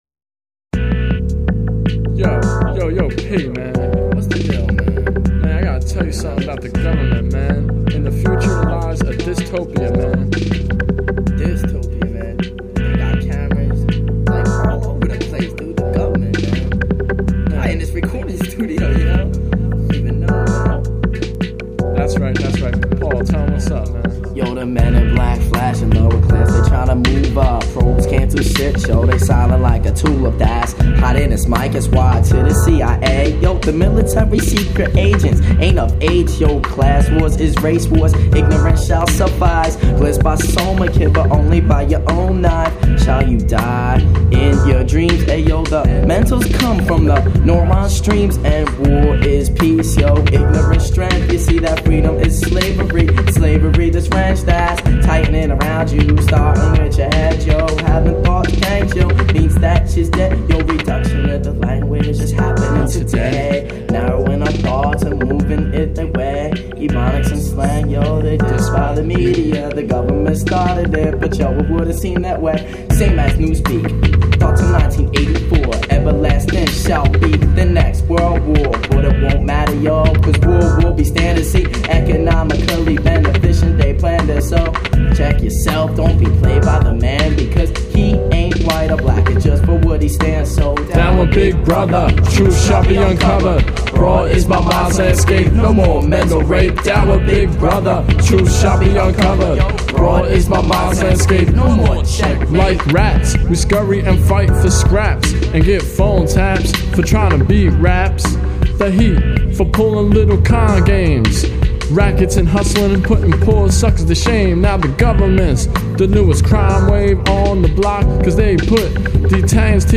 I’ve talked a little about the political hiphop group